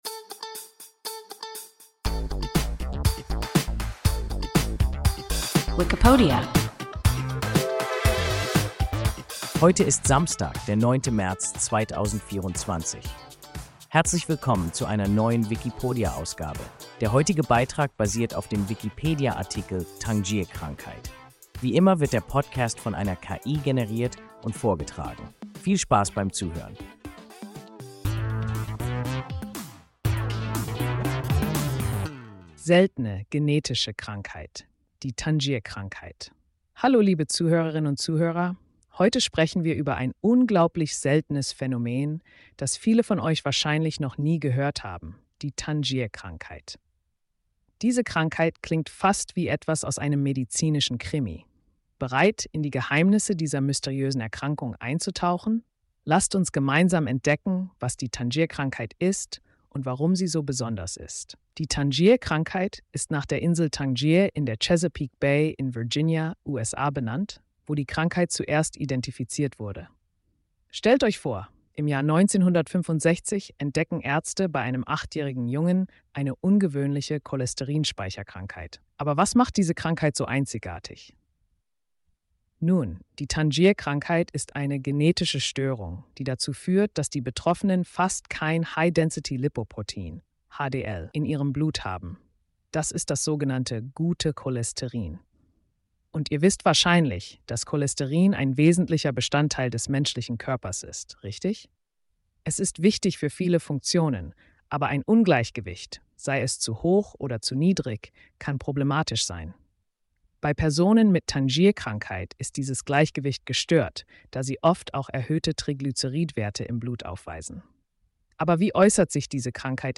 Tangier-Krankheit – WIKIPODIA – ein KI Podcast